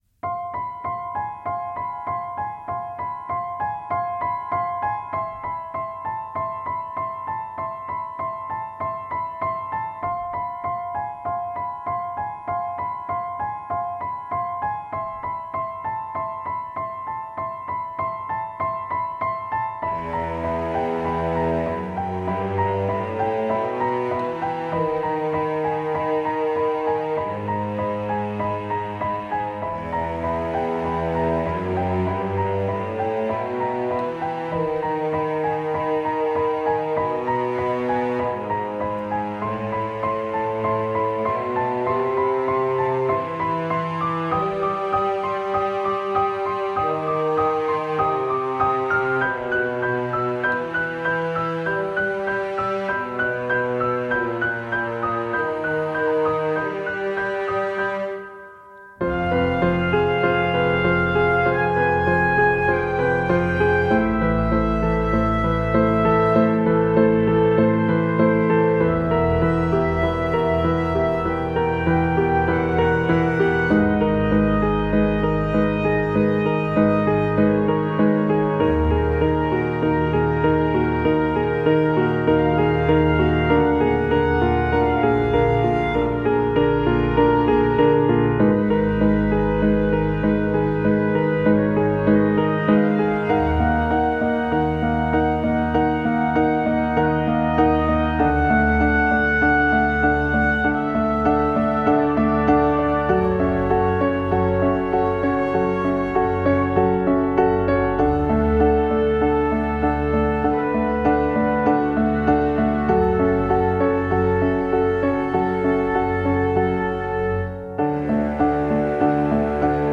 Жуткая мистическая музыка